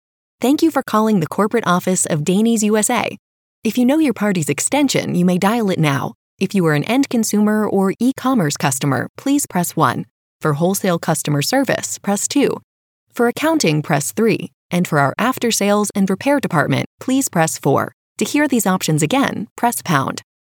Female Voice Over Talent
Friendly, Warm, Conversational.
IVR, Phones